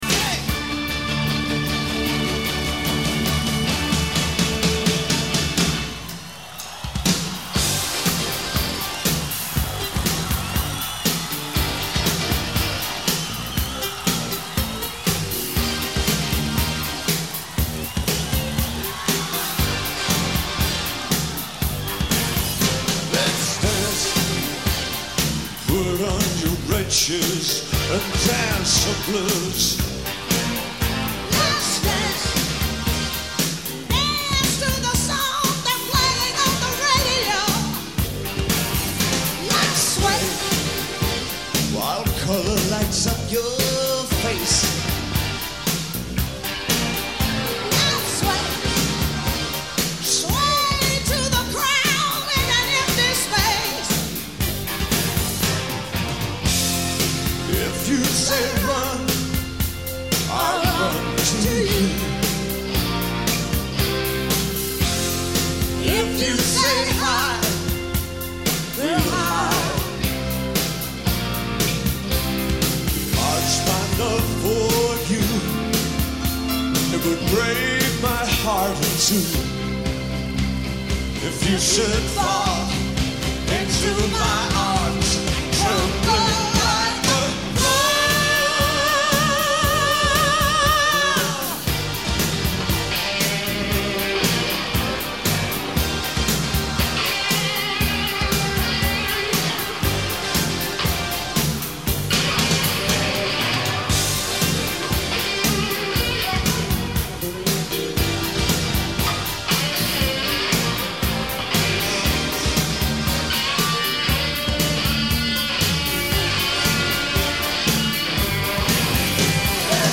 Tempo : 116